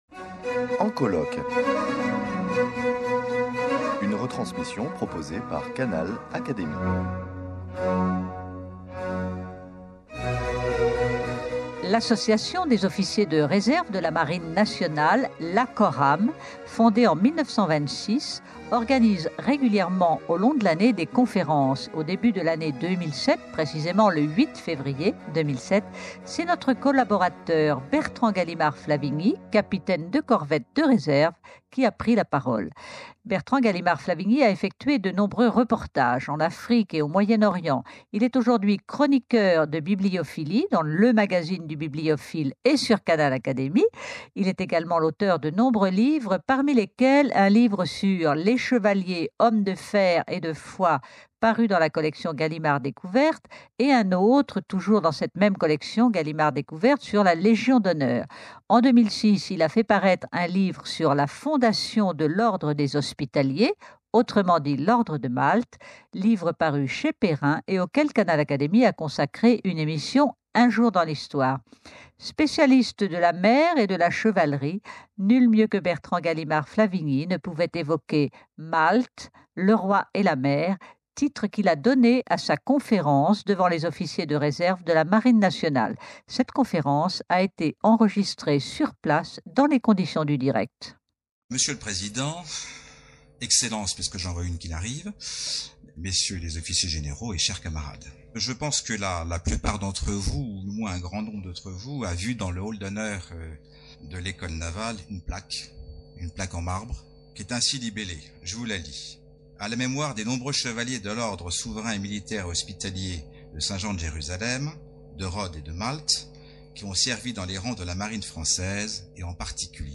Cette émission vous propose d'écouter la conférence enregistrée dans les conditions du direct